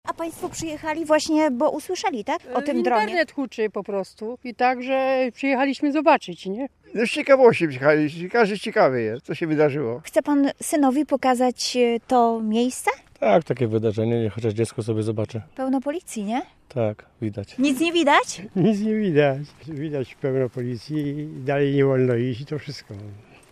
Dron jest w jednym kawałku, ma nieco ponad metr kwadratowy powierzchni. Pracy policjantów, prokuratorów i żołnierzy z daleka przypatrują się okoliczni mieszkańcy. "Przyjechaliśmy zobaczyć, co się dzieje" - mówią reporterce Radia Poznań.